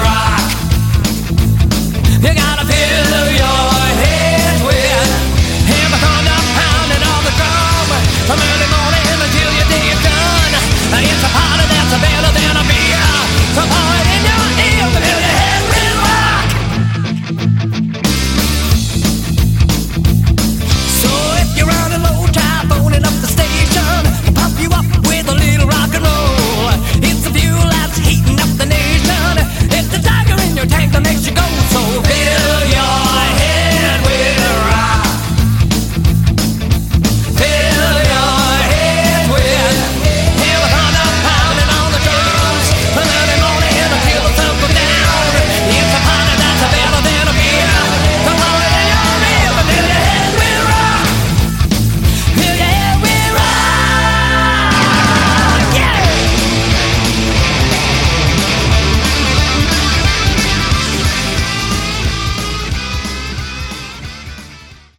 Category: Hard Rock
Vocals
Bass
Drums
Guitar